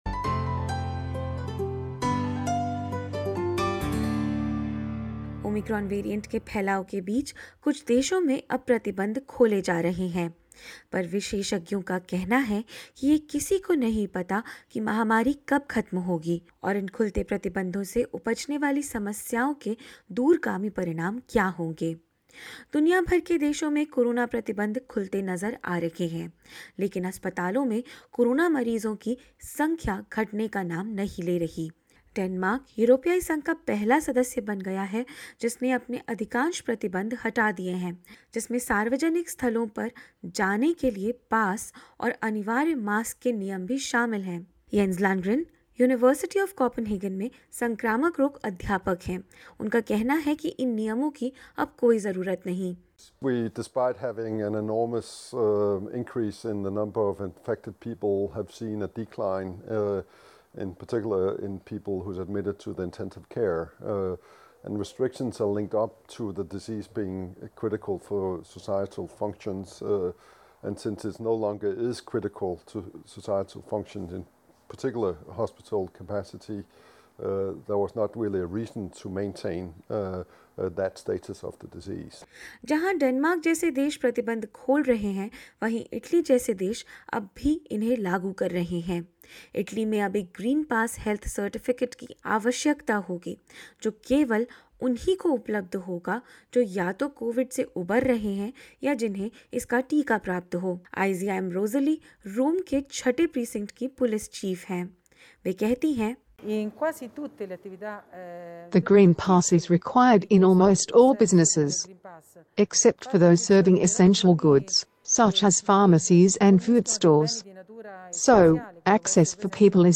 साथ ही कोरोना टीकाकरण अभियान और इलाज से बढ़ने वाला चिकित्सीय कचरा भी परेशानी का बड़ा कारण है। क्या है संगठन अधिकारियों का कहना और क्या है विशेषज्ञों की राय, जानेंगे इस रिपोर्ट में।